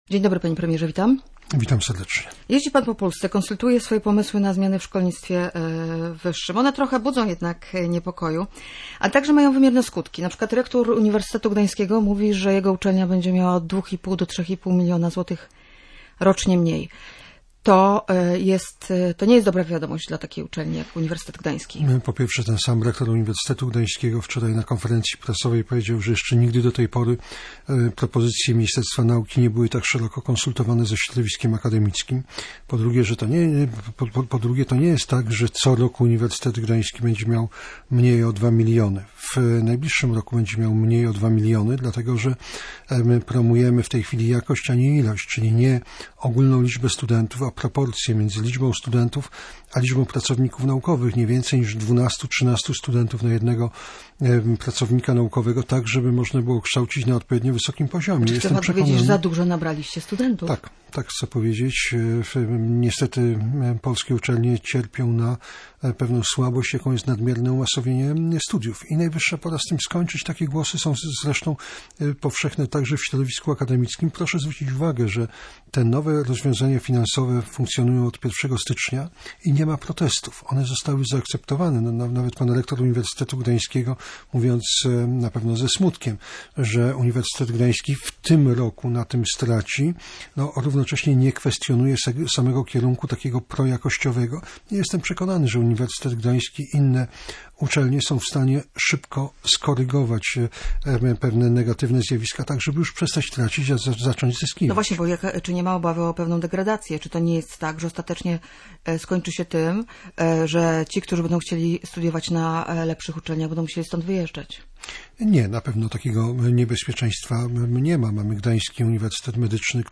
Jarosław Gowin, wicepremier, minister nauki i szkolnictwa wyższego, był gościem Rozmowy kontrolowanej.